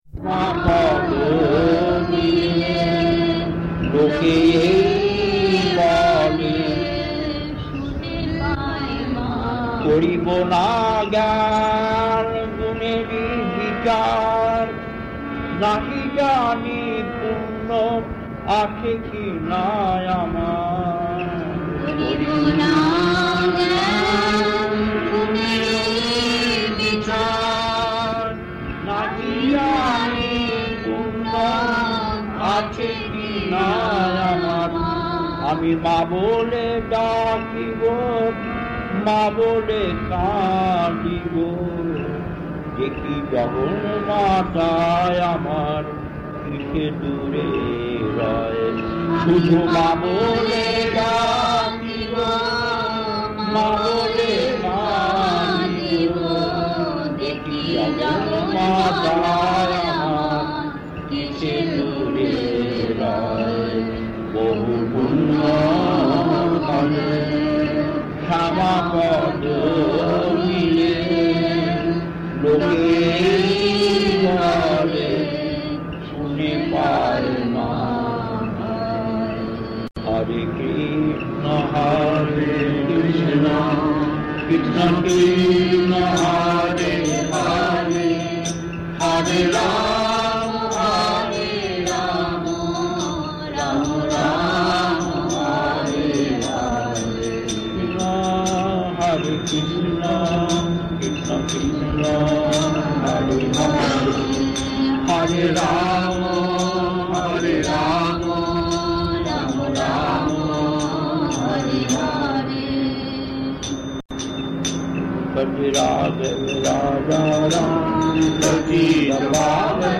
Kirtan A11-2 Gold Croft early 90's 1.